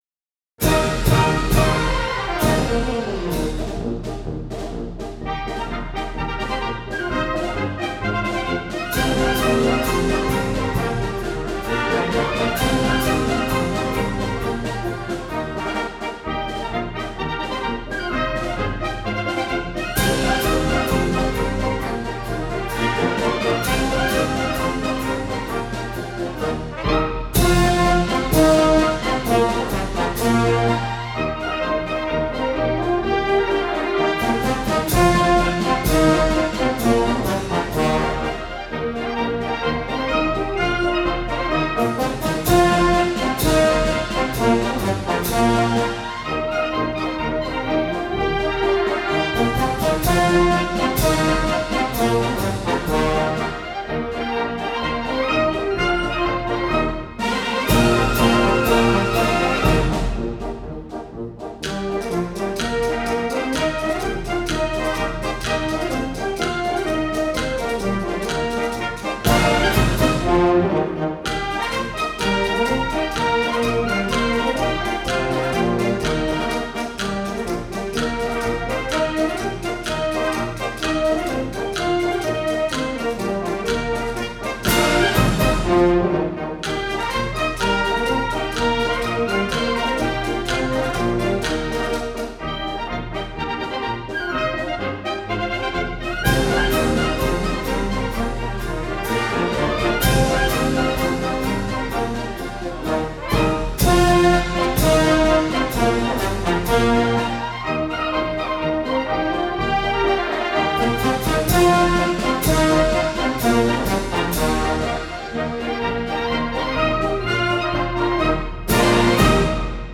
军乐